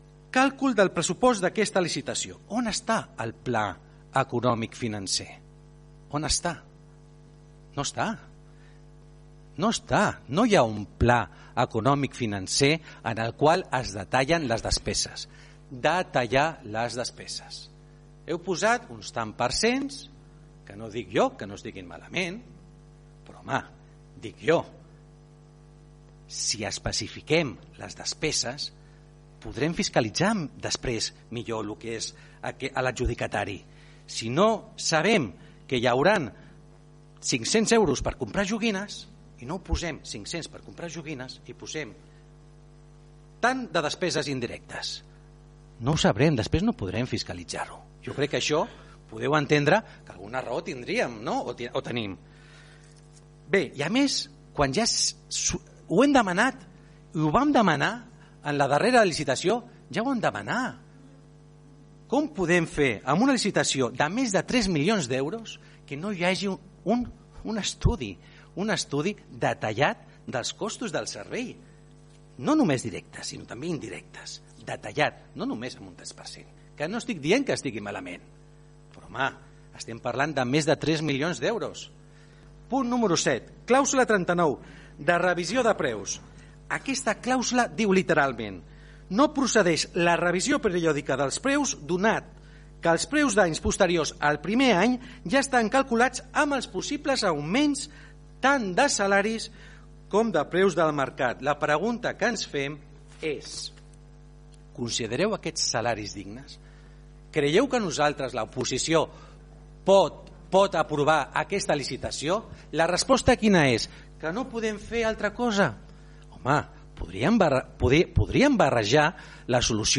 Pel que fa al portaveu del PSC, Miquel Santiago, va destacar la manca d’un estudi econòmic financer i va dir que s’hauria d’haver inclòs el detall de les despeses per poder fiscalitzar millor la tasca de l’empresa concessionària: